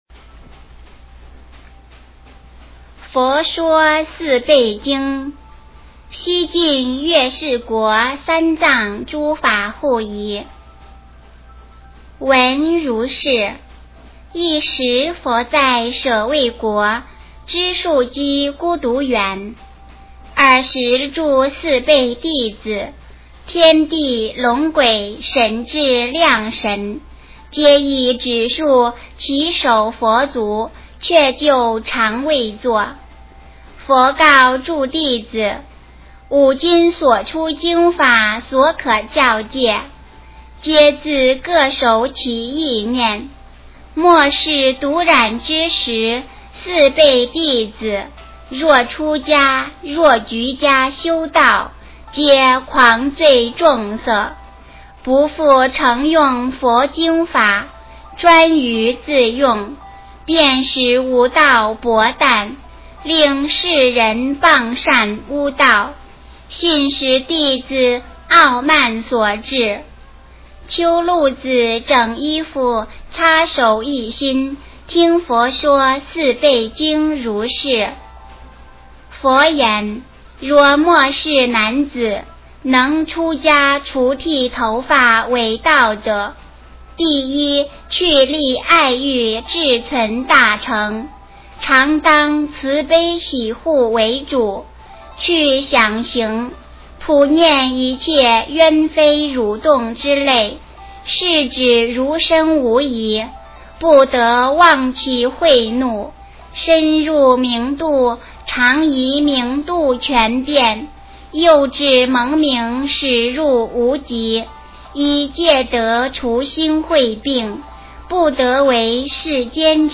诵经
佛音 诵经 佛教音乐 返回列表 上一篇： 佛说盂兰盆经 下一篇： 佛说十八泥犁经 相关文章 心经 心经--邝美云...